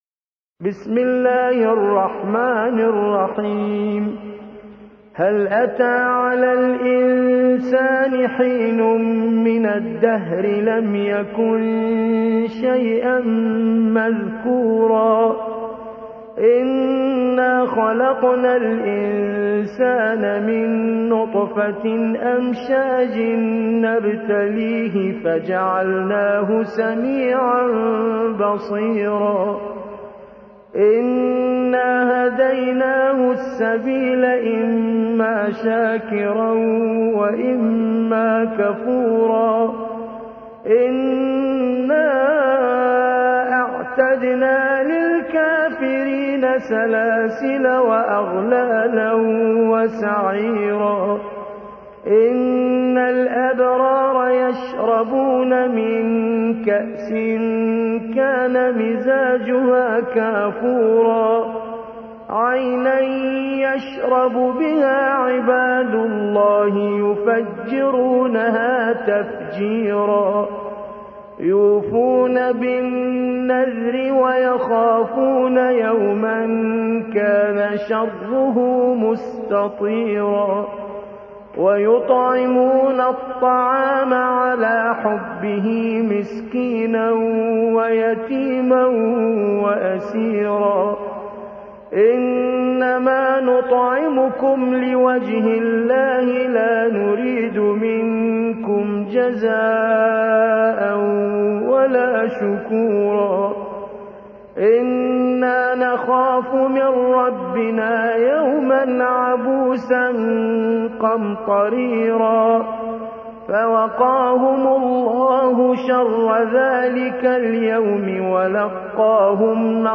76. سورة الإنسان / القارئ